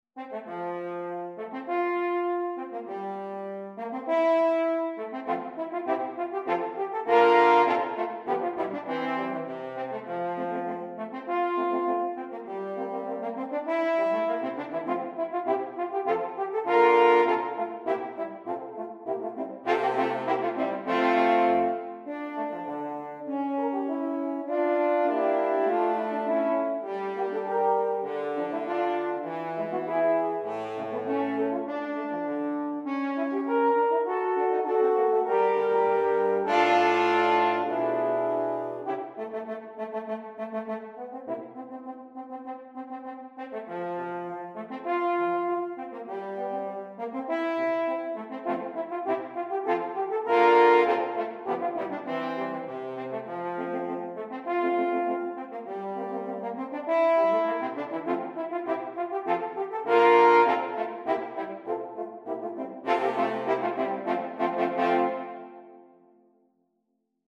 Voicing: French Horn Quartet